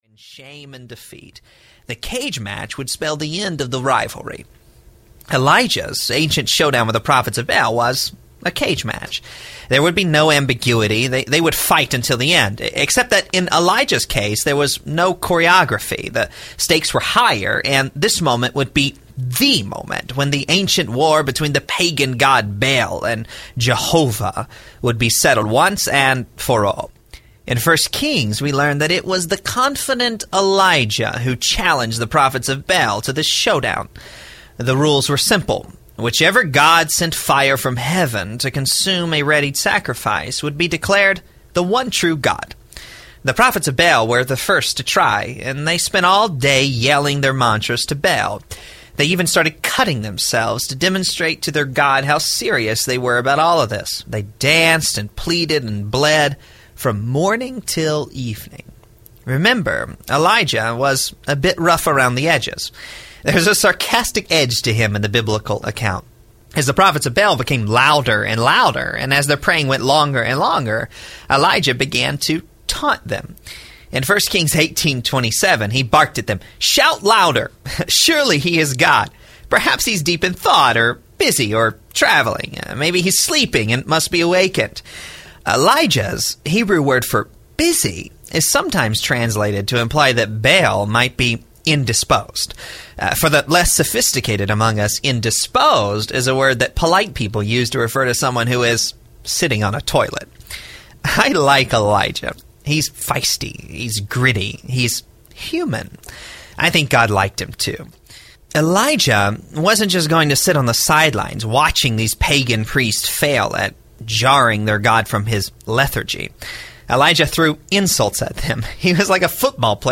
Dirty God Audiobook